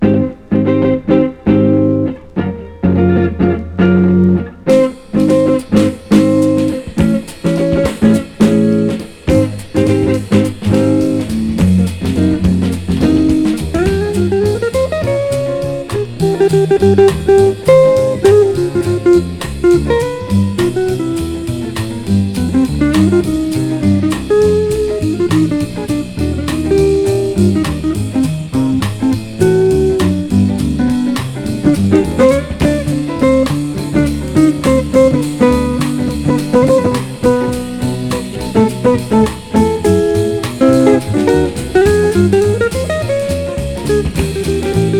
ギター、オルガンの他、フルート、ベース、ドラムといった編成。"
グルーヴィーさ有り、ドラマチックさ有り、通して抑揚の効いた楽曲と演奏に思わず情景が浮かびます。